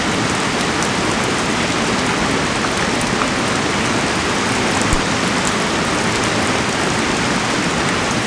1 channel
L_RAIN3.mp3